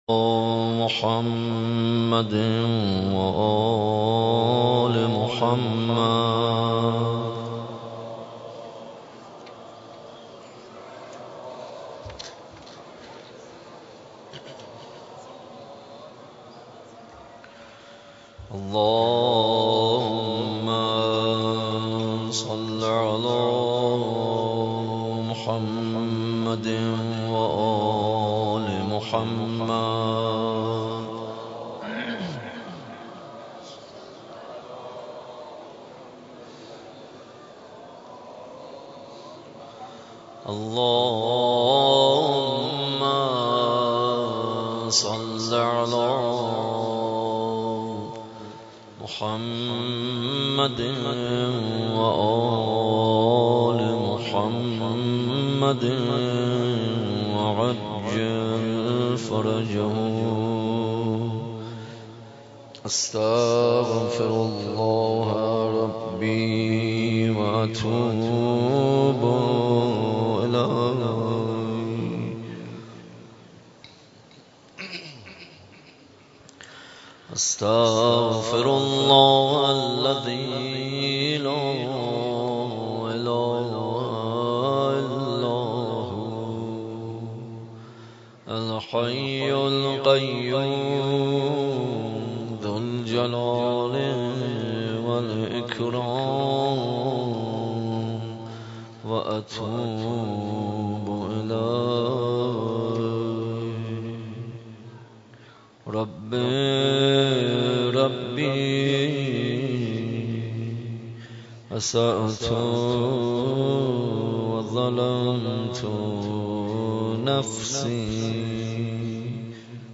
در مسجد امام حسین (ع) واقع در میدان امام حسین(ع) برگزار گردید.
دعای ابوحمزه